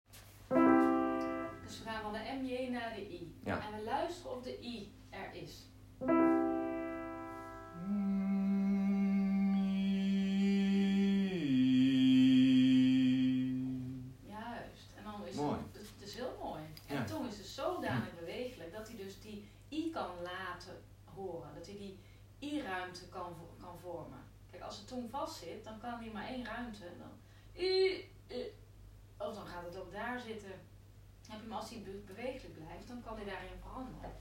Oefening 5 verbinding MJ met IE
Deze oefening verbindt de MJ met de vocaalruimte i